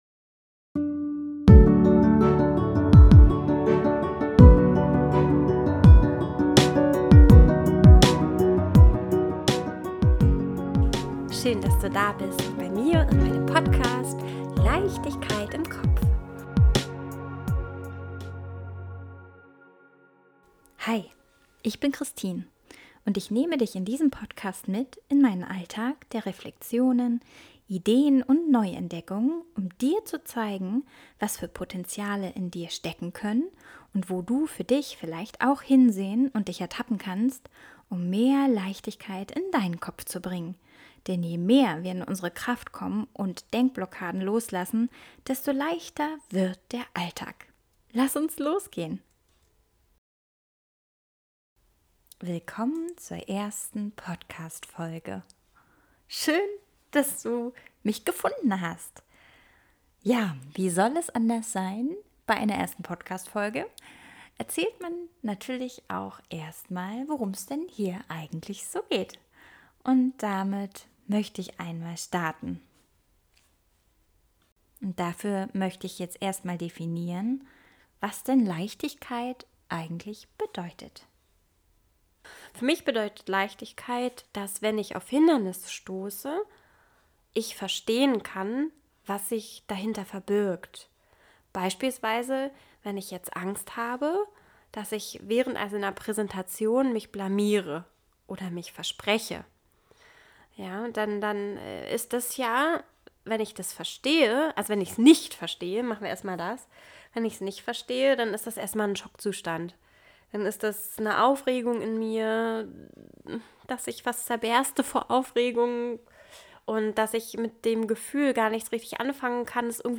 Intro und Outro Musik